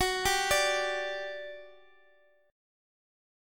G5/F# chord {x x x 11 8 10} chord